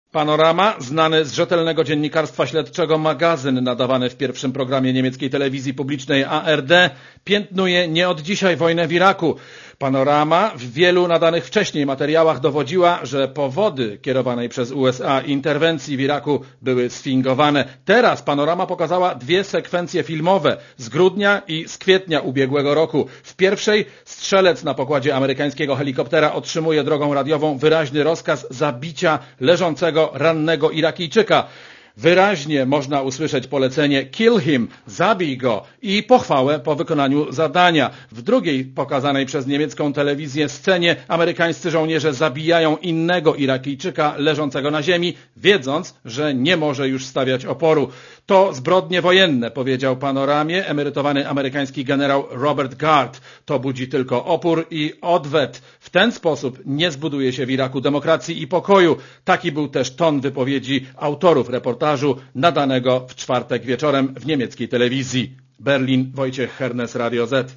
Więcej w relacji korespondenta Radia Zet